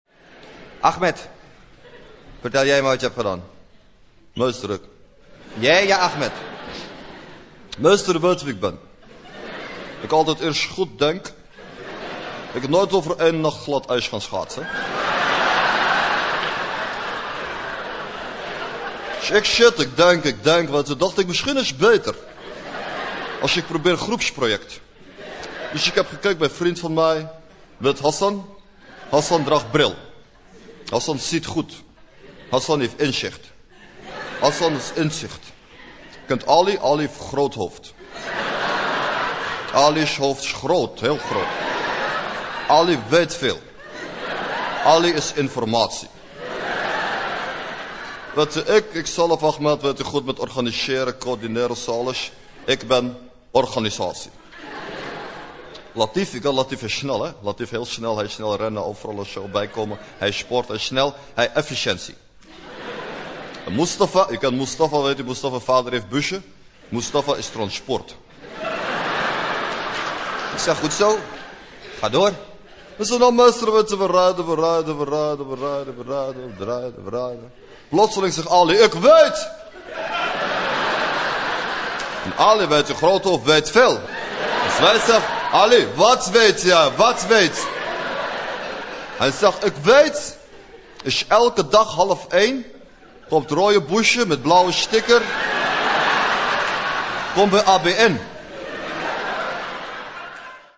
Drie fragmenten uit shows.